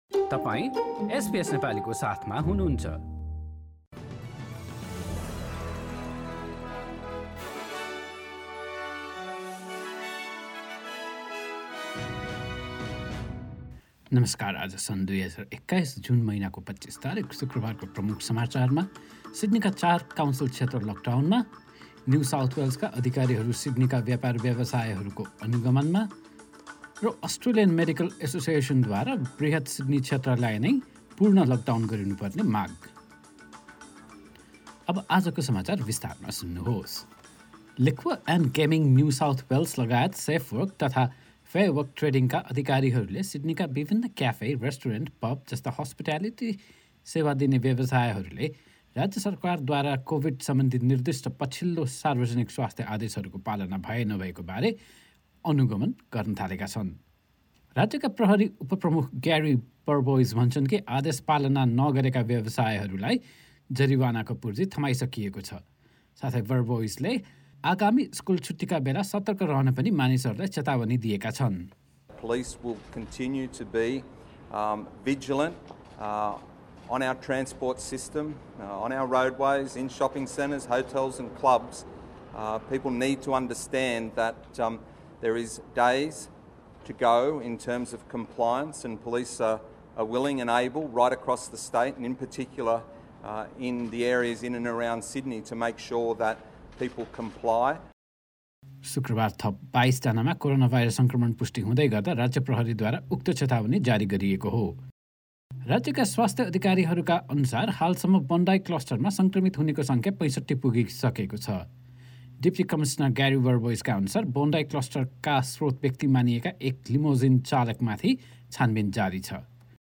एसबीएस नेपाली अस्ट्रेलिया समाचार: शुक्रवार २५ जुन २०२१